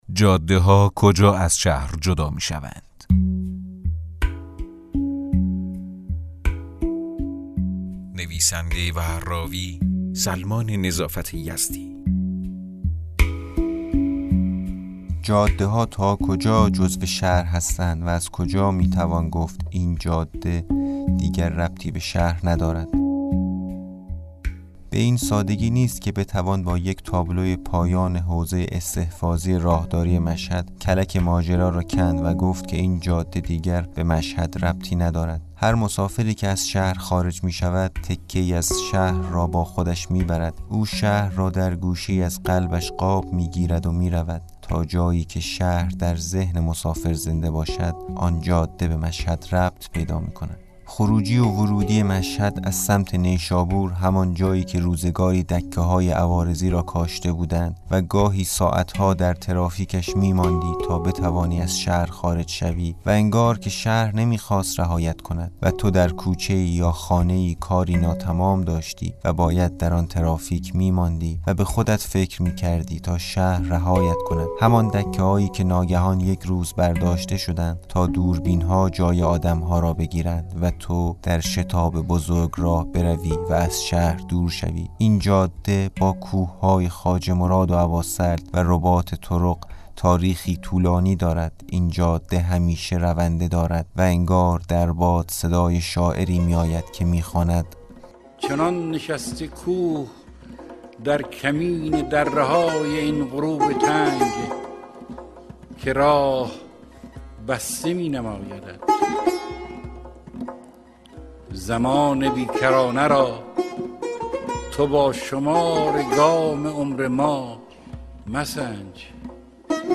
داستان صوتی: جاده‌ها کجا از شهر جدا می‌شوند؟